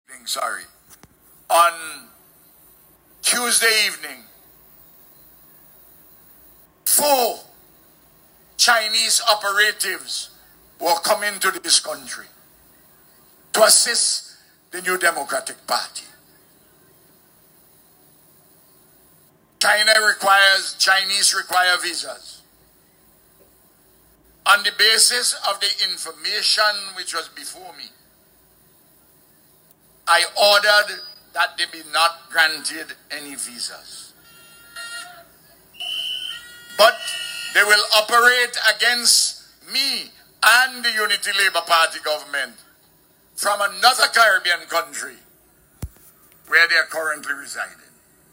Addressing supporters at a public meeting in North Central Windward, Gonsalves said he had personally intervened to deny visas to individuals he described as “Chinese operatives.”
The remarks drew strong reactions from those in attendance and underscored the increasingly heated political atmosphere as the country moves closer to general elections.
Part Audio Recording from ULP’s meeting tonight at NCW.